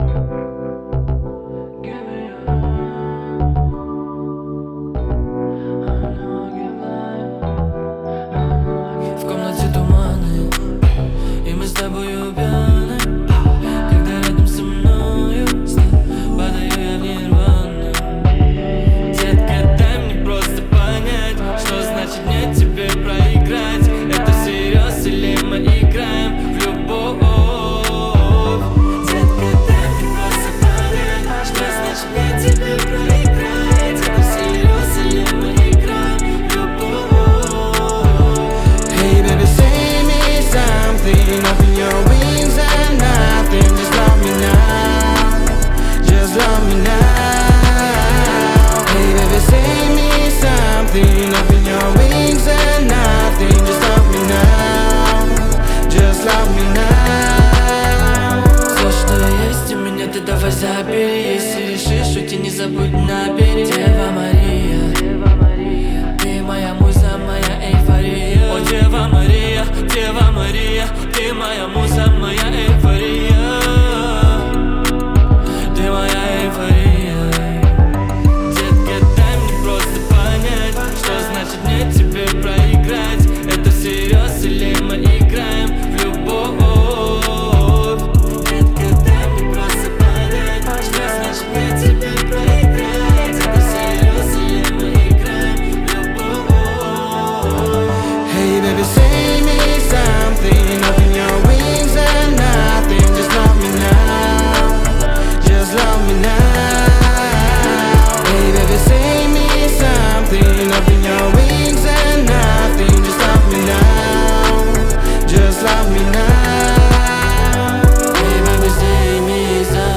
это яркая композиция в жанре R&B